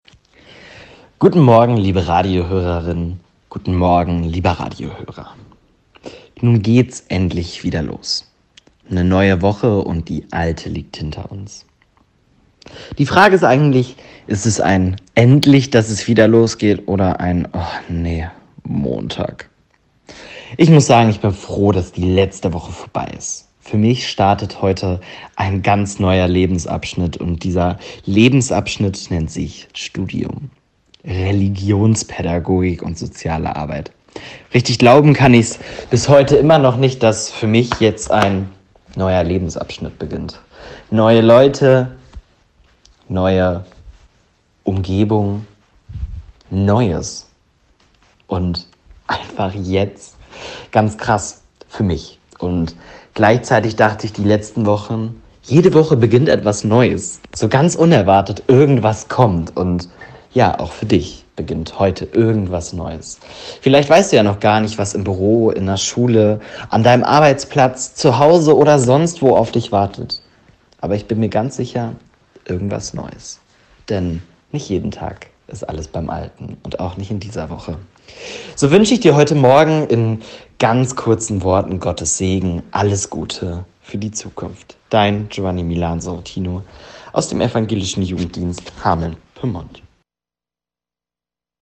Kirche: Radioandacht vom Montag, 19. September